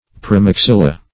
Praemaxilla \Pr[ae]`max*il"la\, n.